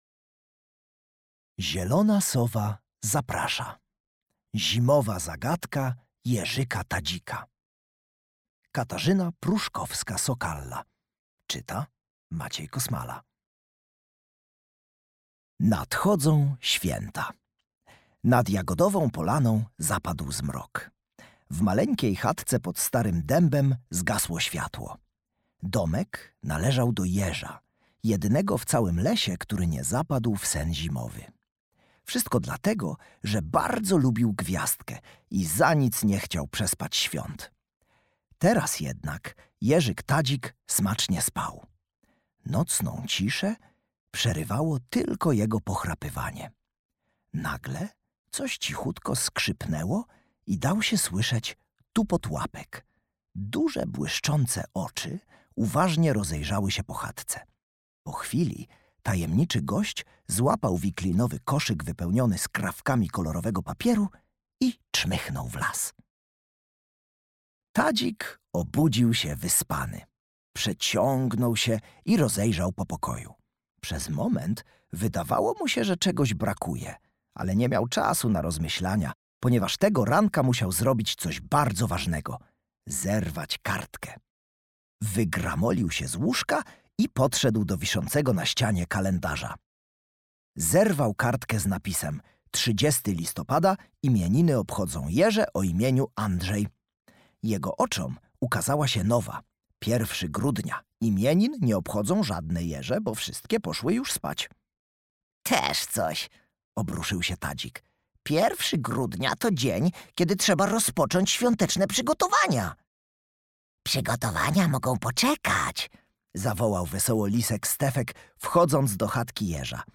Zimowa zagadka Jeżyka Tadzika - Katarzyna Pruszkowska-Sokalla - audiobook + książka